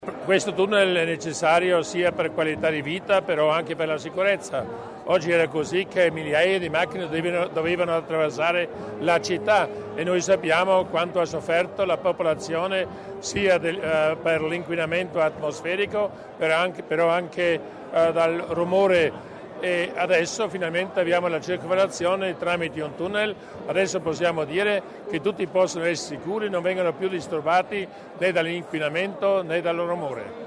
L'Assessore Mussner sul valore del progetto
Uno slogan che si adatta alla perfezione alla cerimonia di questo pomeriggio (16 aprile) per l’inaugurazione del primo tratto della nuova circonvallazione di Bressanone, che sarà definitivamente transitabile a partire da lunedì 18.